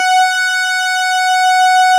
snes_synth_066.wav